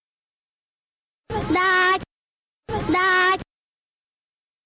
uitspraak Daag !